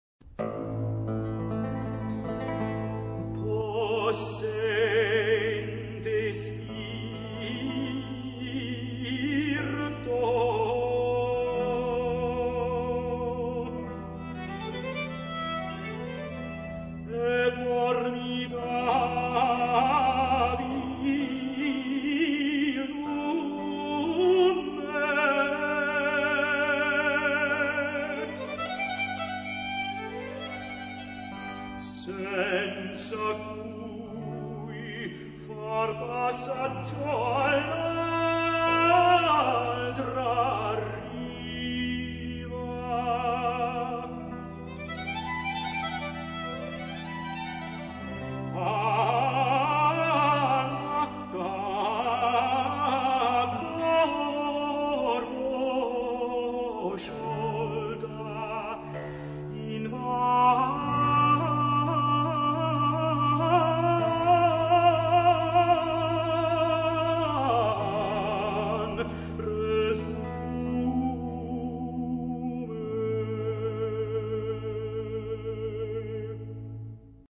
Dat werd het recitatief: solozang met een instrumentale begeleiding.